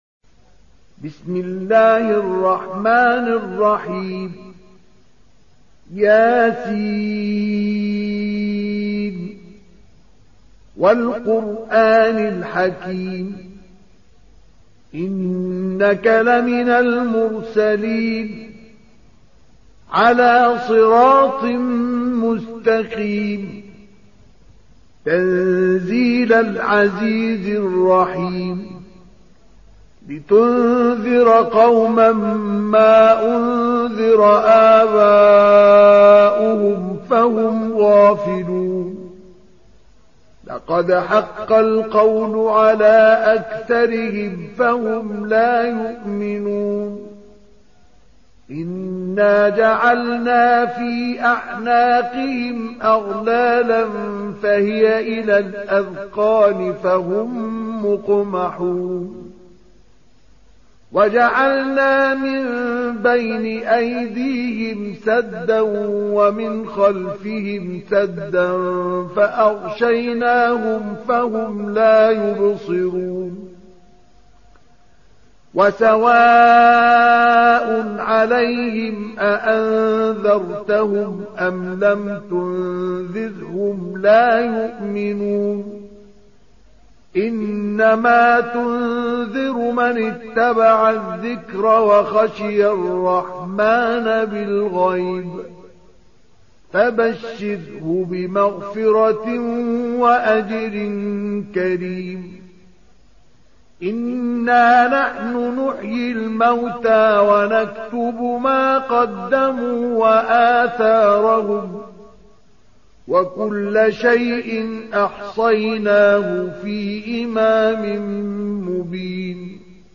ترتیل سوره مبارکه یس با صدای استاد مصطفی اسماعیل